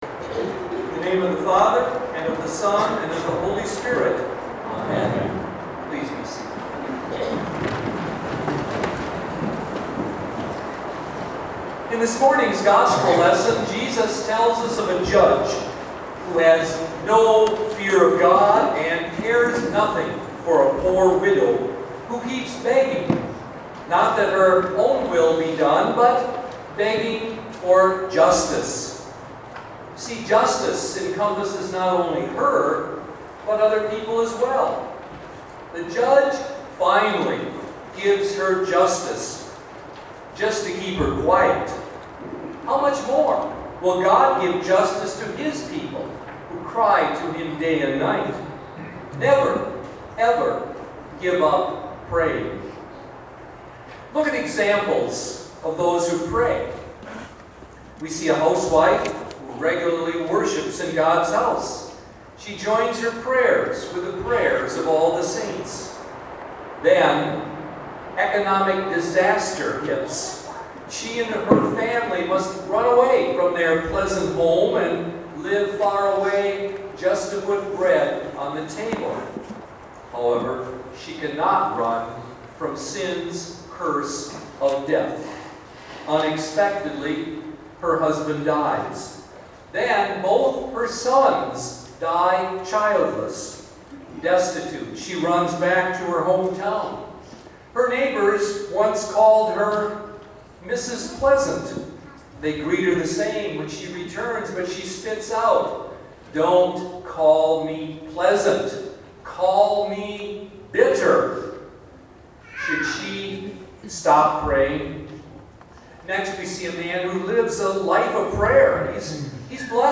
Past Sermons (Audio) - St. Luke Lutheran Church